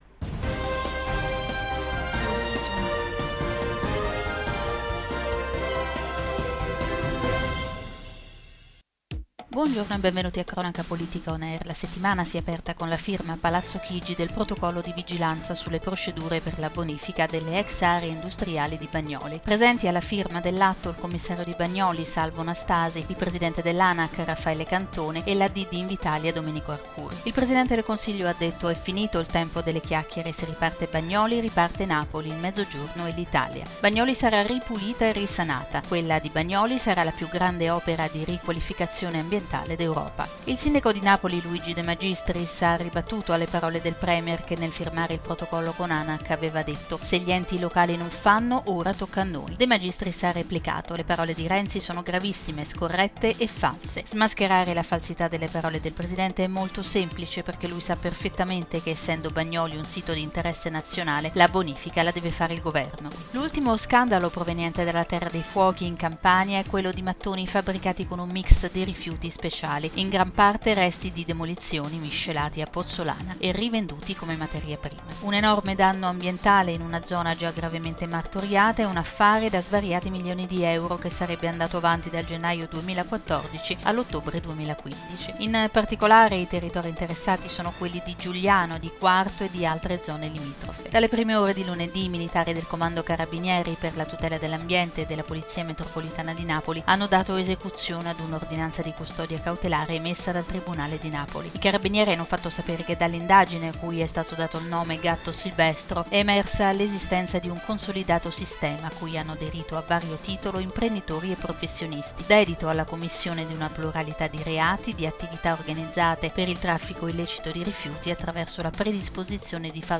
Notiziario 29/05/2016 - Cronaca politica